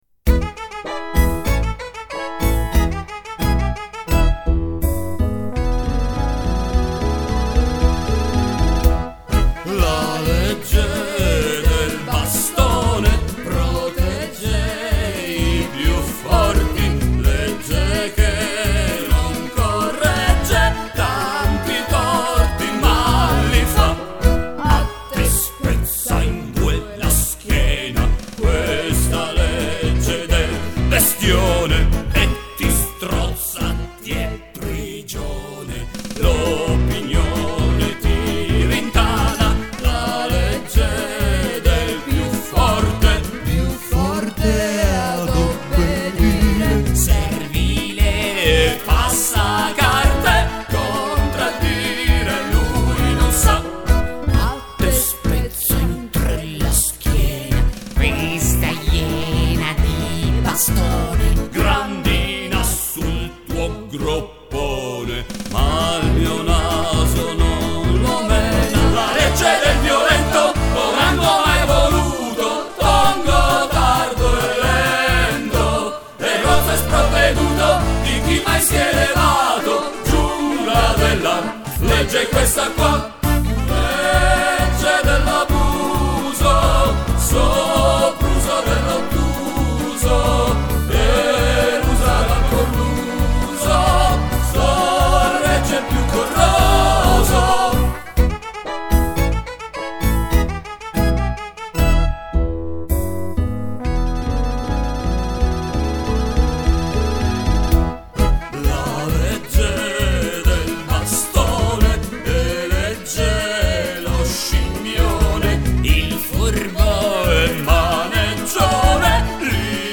Melologo e pantomima musicale